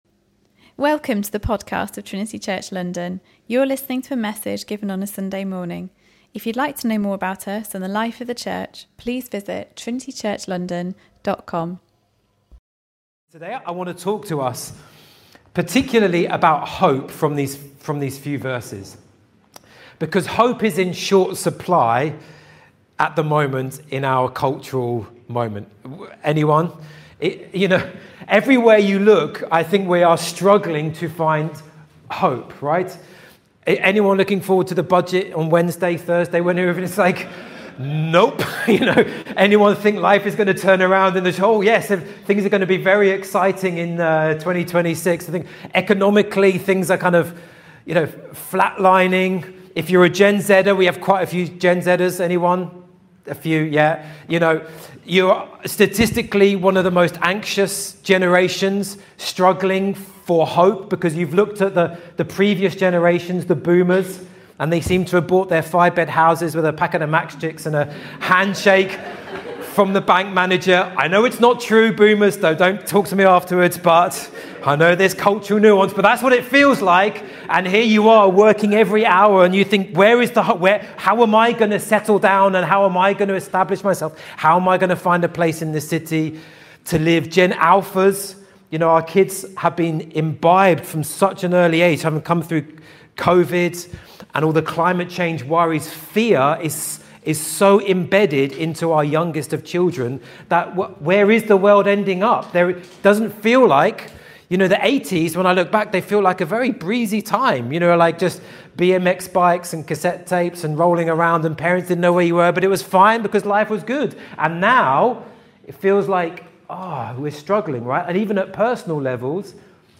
In a world full of difficulties, trials, and suffering, where do we find our hope? Where is God in those moments? In this sermon